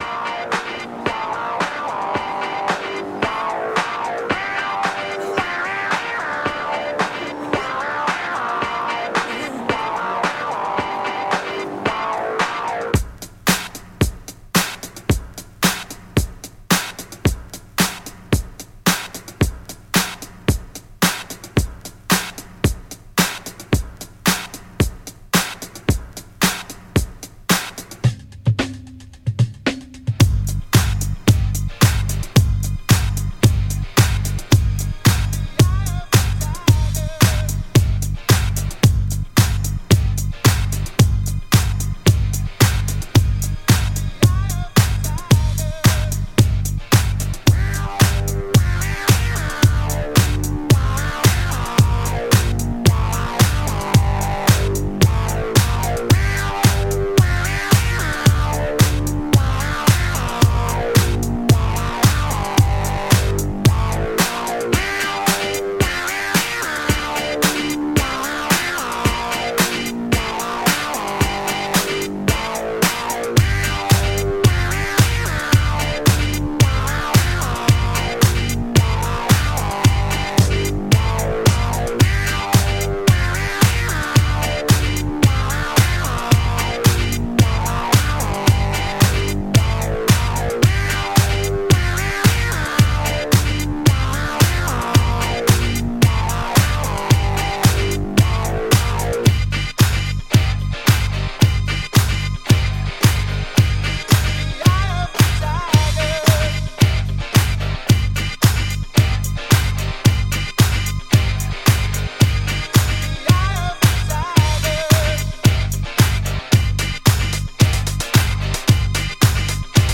Genres: Hip Hop, Rock, Top 40